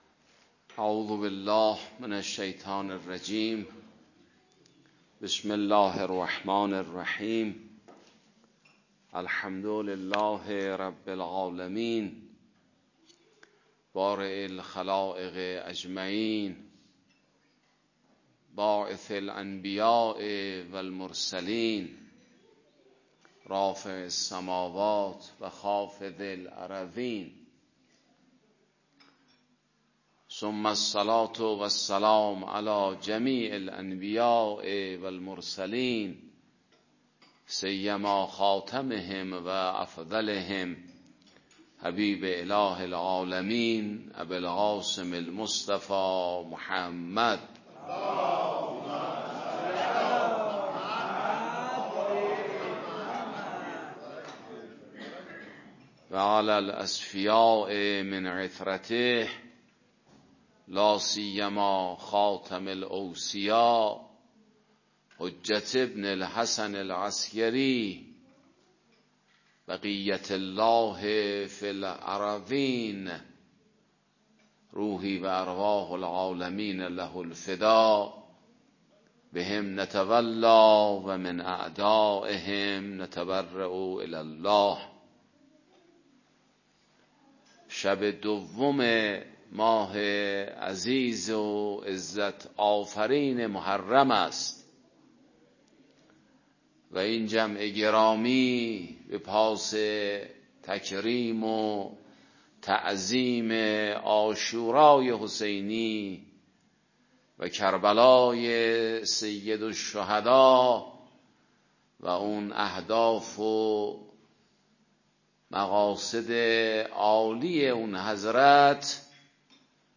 در جمعی از مؤمنان و ارادتمندان اهل بیت عصمت و طهارت، برگزار گردید.